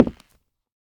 Minecraft Version Minecraft Version latest Latest Release | Latest Snapshot latest / assets / minecraft / sounds / block / nether_wood / step5.ogg Compare With Compare With Latest Release | Latest Snapshot
step5.ogg